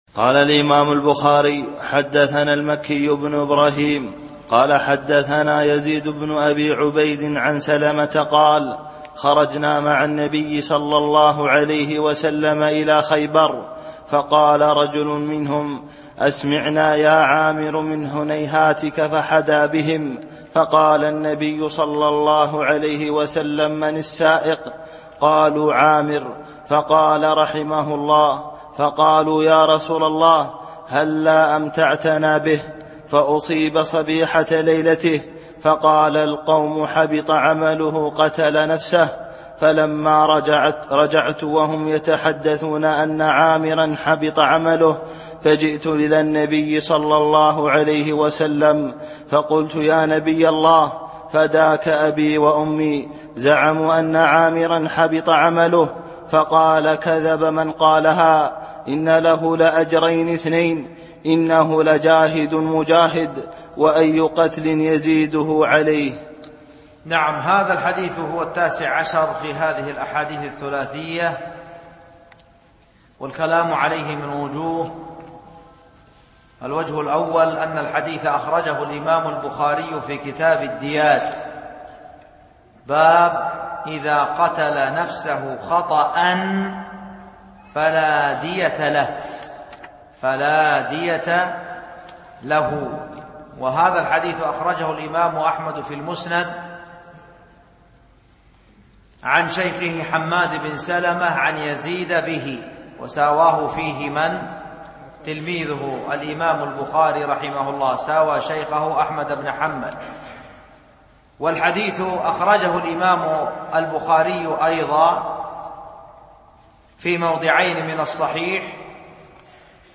الألبوم: شبكة بينونة للعلوم الشرعية المدة: 27:31 دقائق (6.34 م.بايت) التنسيق: MP3 Mono 22kHz 32Kbps (VBR)